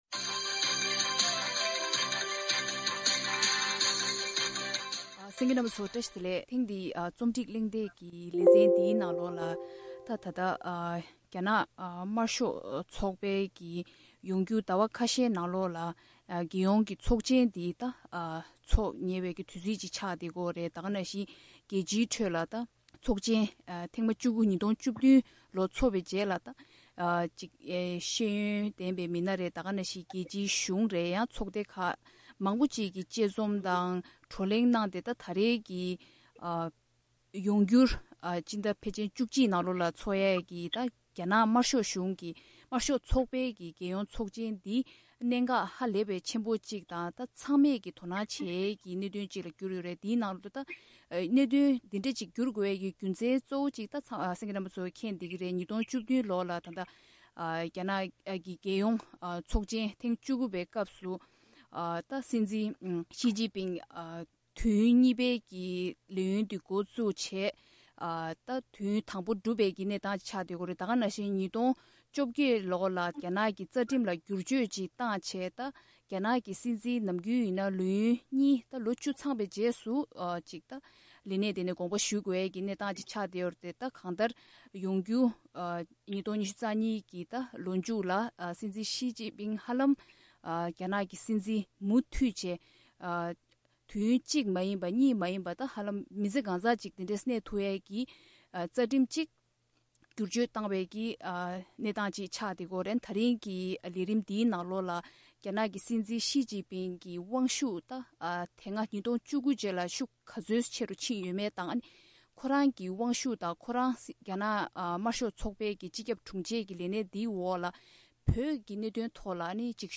དཔྱད་གླེང་གནང་བའི་ལས་རིམ།